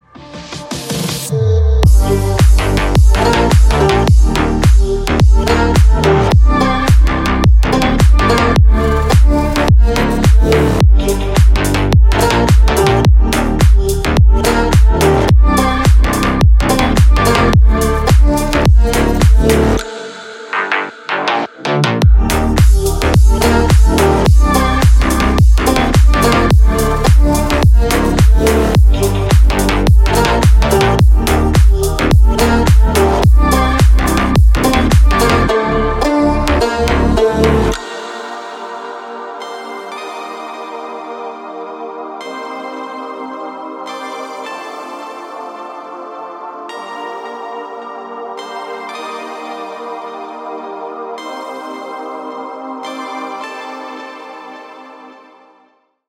• Качество: 320, Stereo
deep house
восточные мотивы
атмосферные
Electronic
EDM
качающие
Атмосферный дип на звонок.